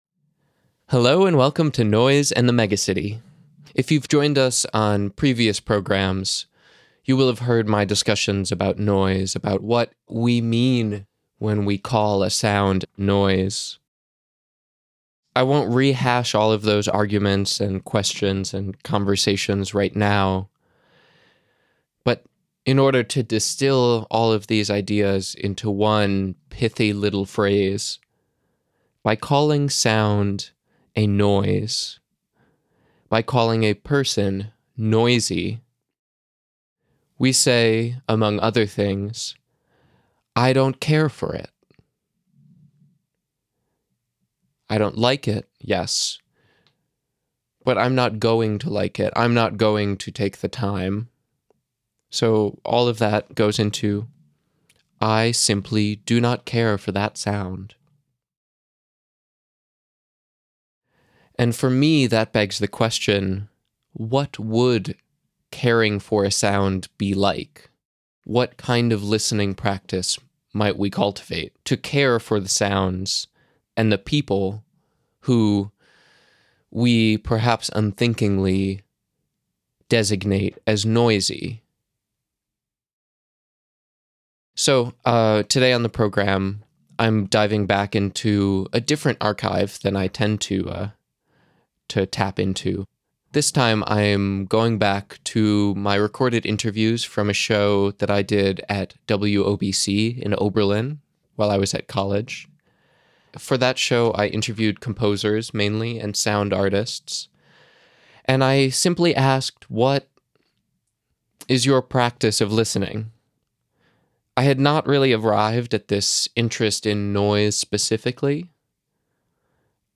To end the show, a mix of music and sound art.